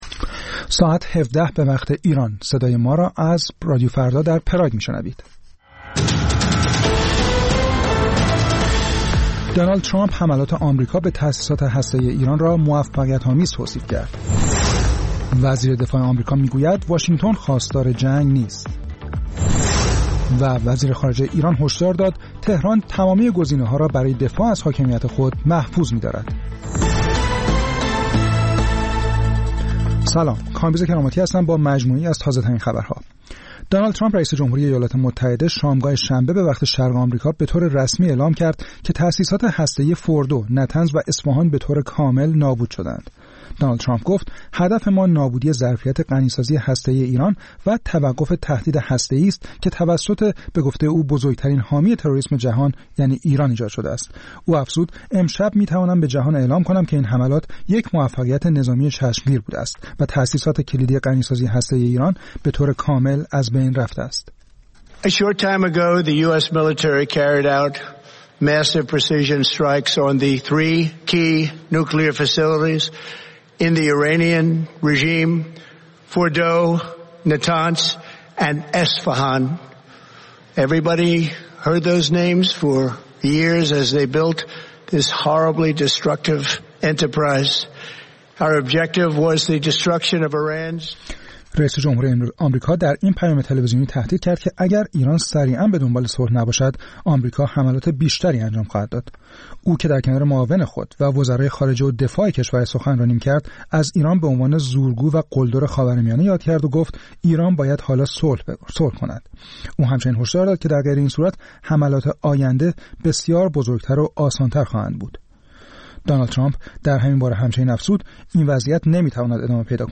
سرخط خبرها ۱۷:۰۰